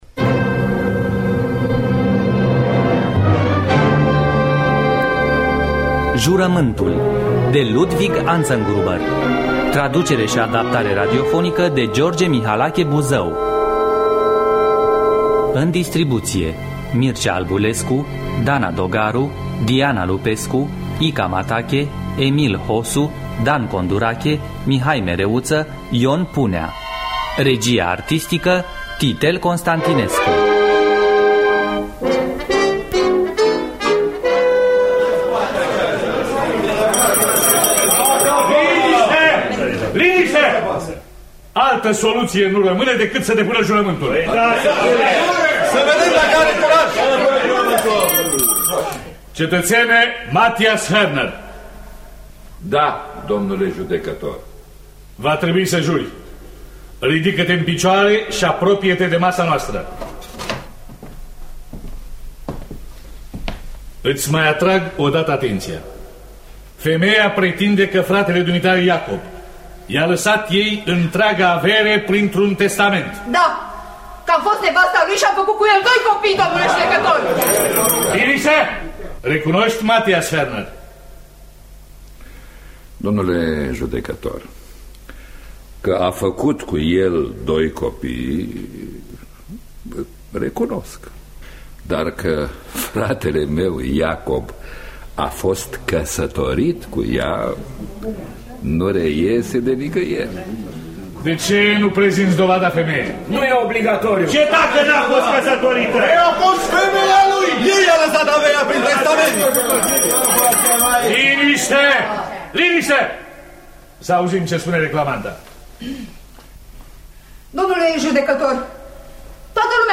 Adaptarea radiofonică de George Mihalache Buzău.